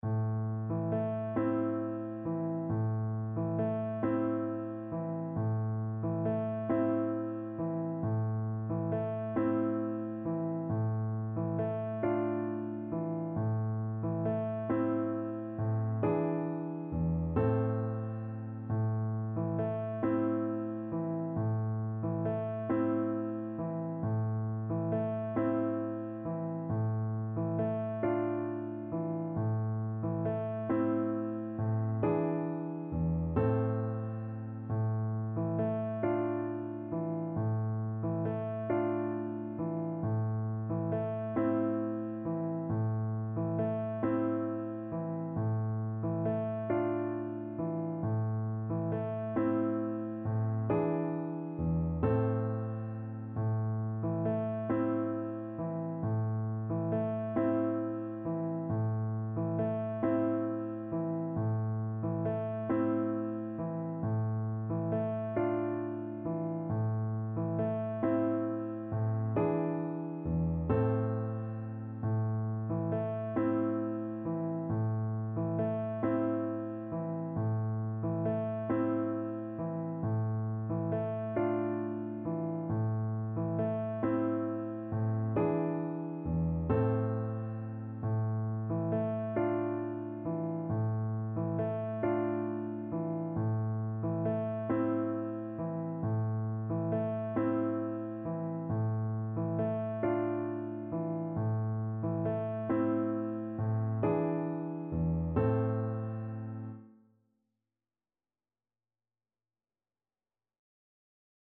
Play (or use space bar on your keyboard) Pause Music Playalong - Piano Accompaniment Playalong Band Accompaniment not yet available reset tempo print settings full screen
Traditional Music of unknown author.
A minor (Sounding Pitch) E minor (French Horn in F) (View more A minor Music for French Horn )
Gently rocking .=c.45
Turkish